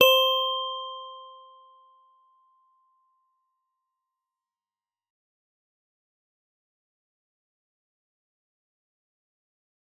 G_Musicbox-C5-f.wav